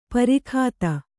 ♪ parai khāta